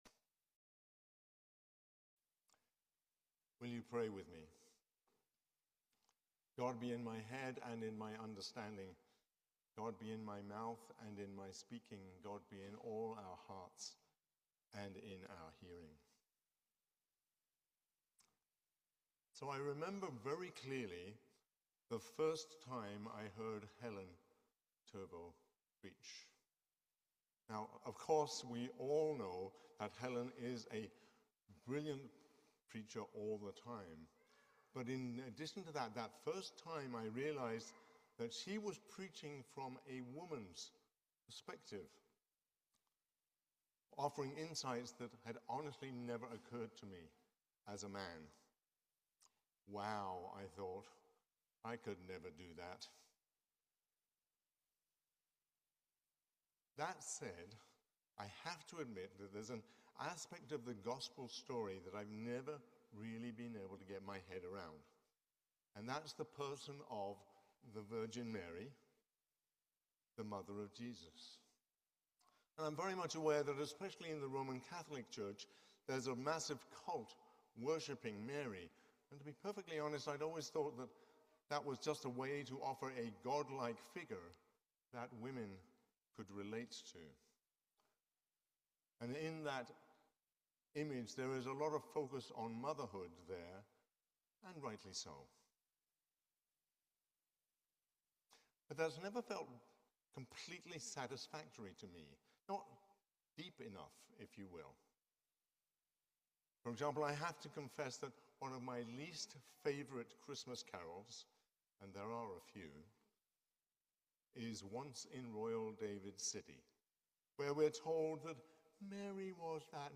Sermon on the Third Sunday of Advent